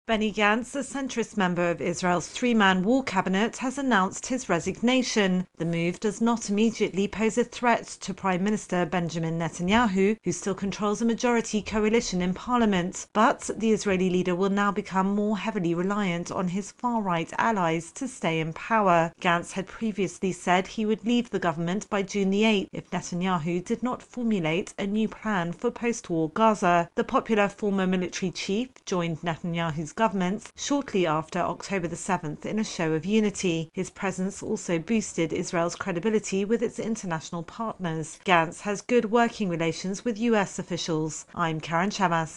reports on the resignation of a centrist member of the Israeli war cabinet.